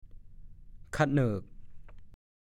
ฐานข้อมูลพจนานุกรมภาษาโคราช
1. คันนาค่อนข้างโต แนวดินที่พูนขึ้น บางทีออกเสียงเป็น ผะเหนิก